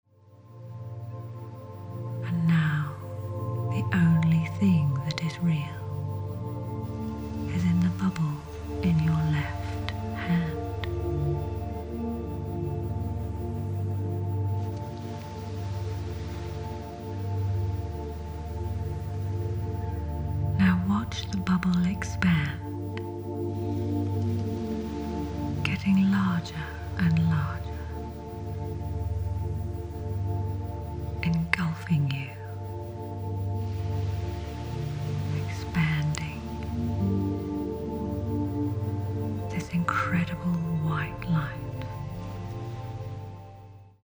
Raise Your Vibration Meditation instant download
Be gently guided out of the chattering mind by going through the relaxation process, then raise the low vibration of fear and worry back to the high vibration of love.